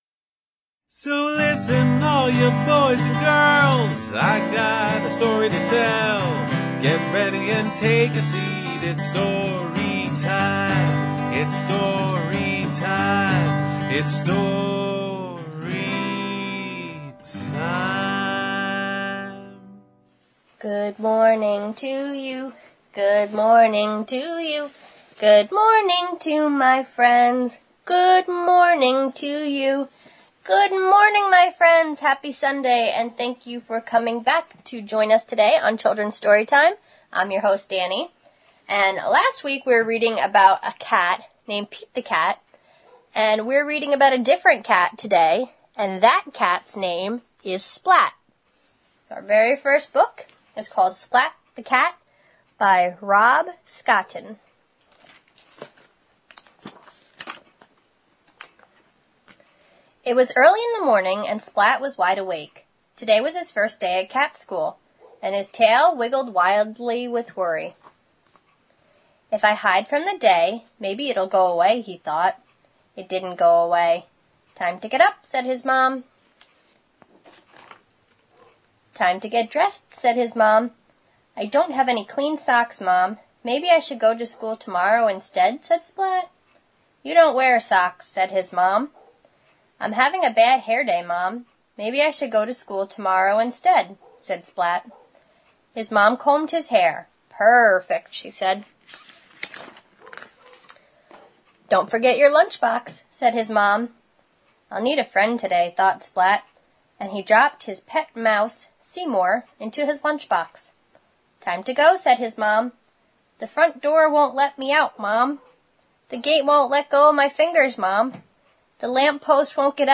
A radio program of a collection of stories, jokes, and songs established to foster a love of books in young children, and to encourage everyone to use their local library.